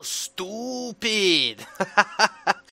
another sub alert
subalert222.mp3